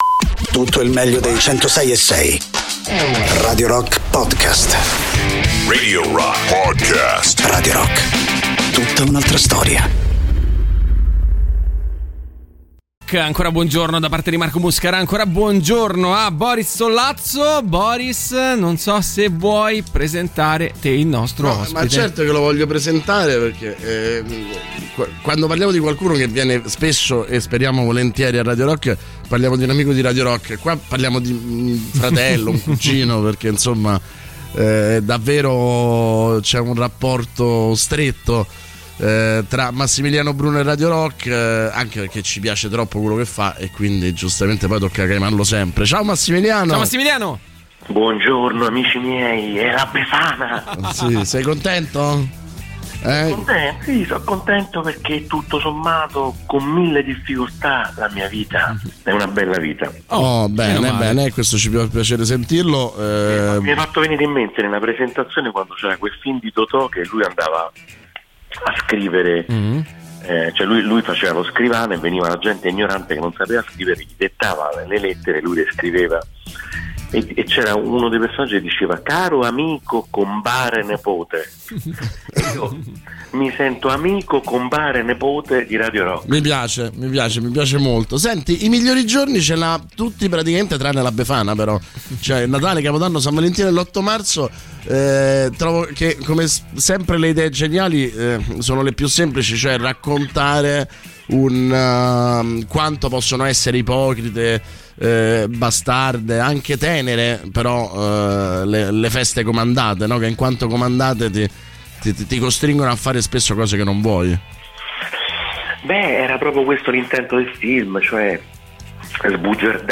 Interviste: Massimiliano Bruno (06-01-23)